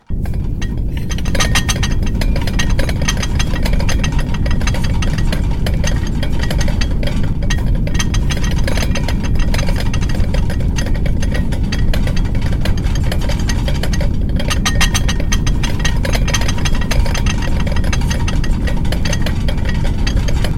食器が地震で揺れ
earthquake_start.mp3